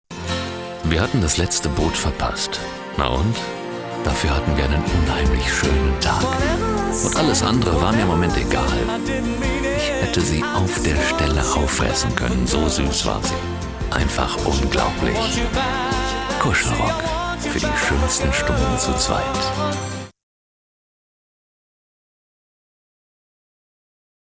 Profi-Sprecher deutsch. Warme freundliche Stimme, Imagefilme, Dokumentationen
norddeutsch
Sprechprobe: Industrie (Muttersprache):
german voice over artist